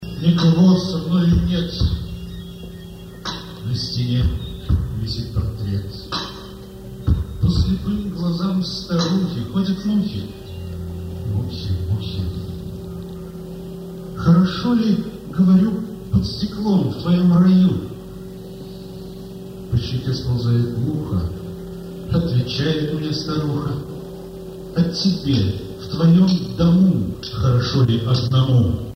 КОНЦЕРТ СО СТИХАМИ
(Концерт с литературной частью г.Куйбышев, ныне Самара.
(Bootleg))